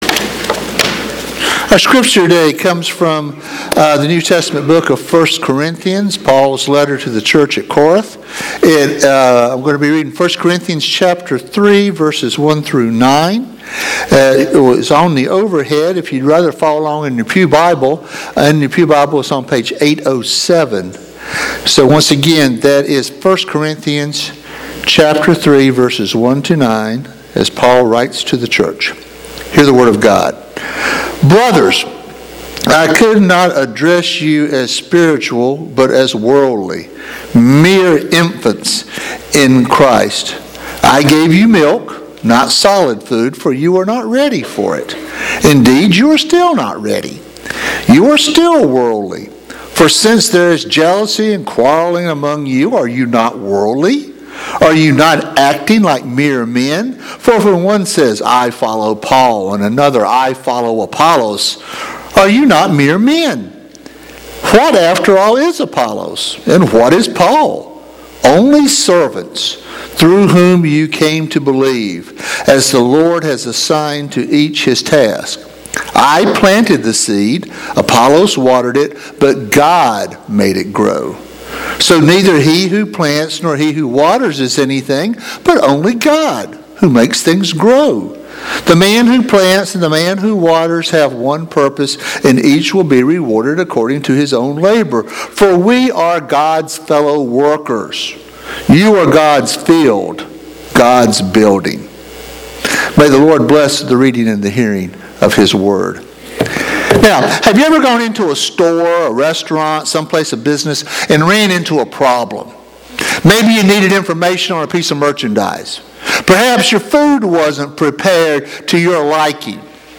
East Naples United Methodist Church Sermons